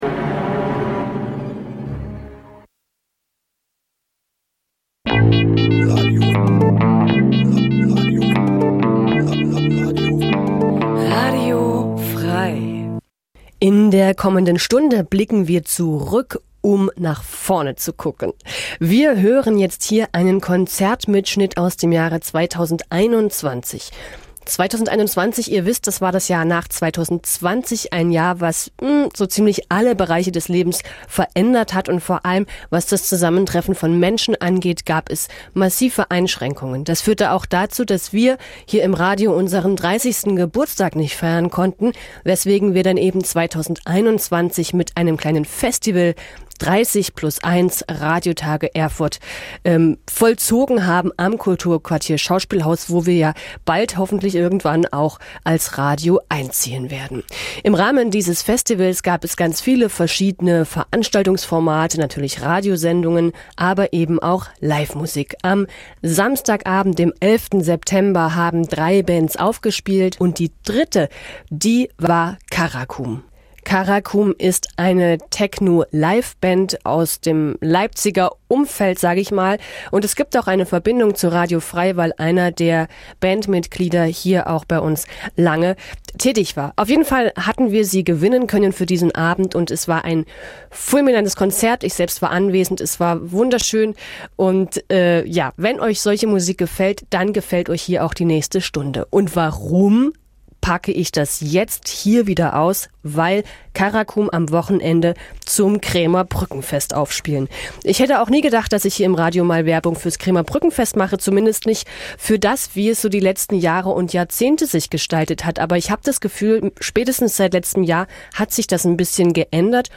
Radiotage Erfurt | KulturQuartier
Konzertmitschnitt